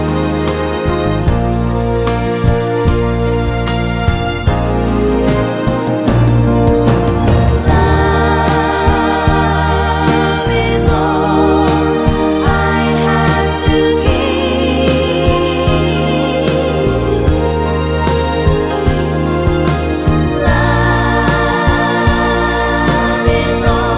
No Backing Vocals Crooners 4:45 Buy £1.50